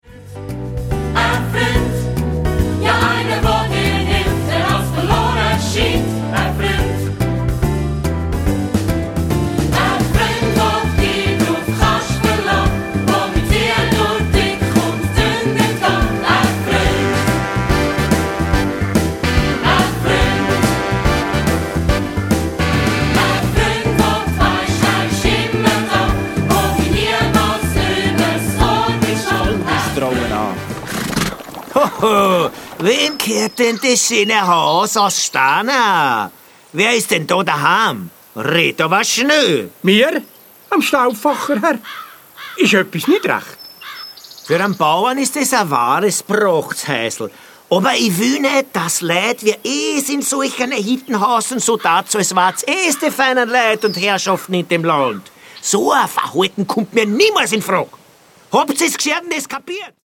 Popmusical